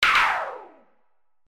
/ F｜演出・アニメ・心理 / F-03 ｜ワンポイント1_エフェクティブ
ピューン